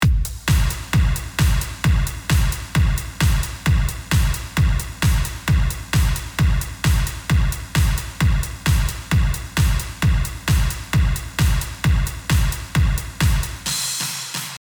Und denselben Ausschnitt, aber mit sehr viel Hall darauf:
Drums_mit_Hall.mp3